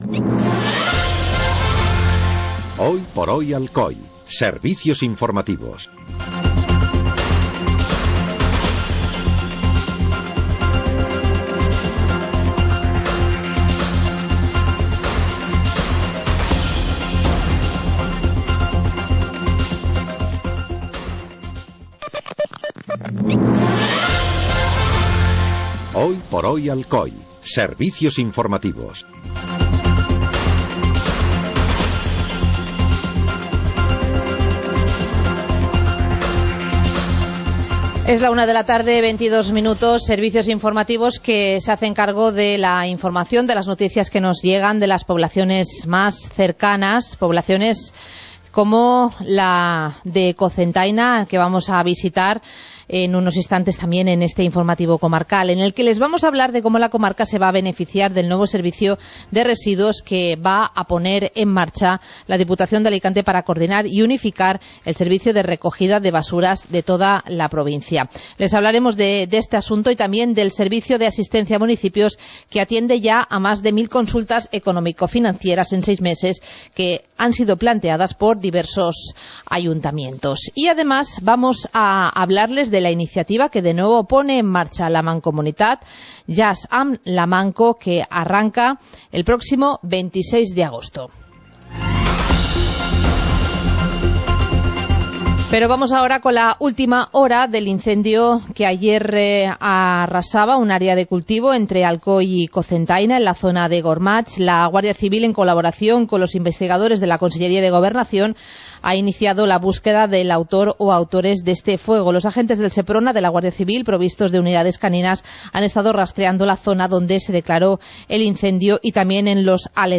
Informativo comarcal - miércoles, 13 de agosto de 2014